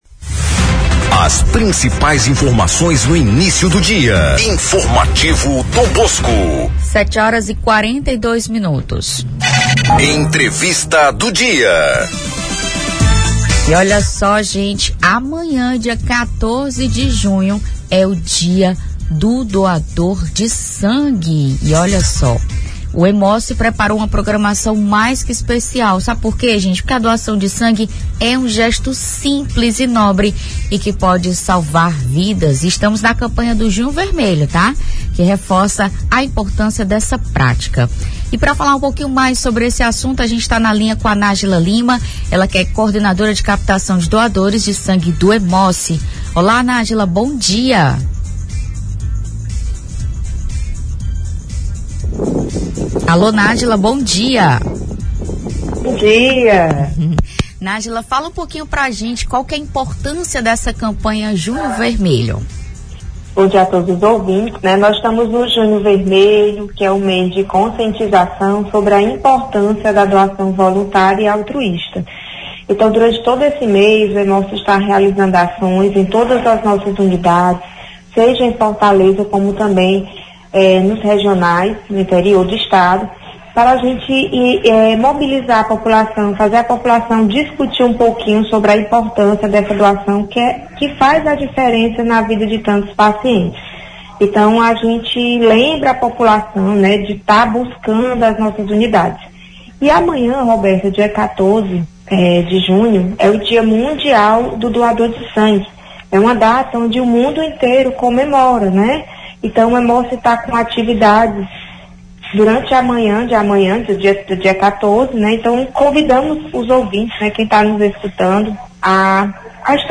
ENTREVISTA-HEMOCE-1306.mp3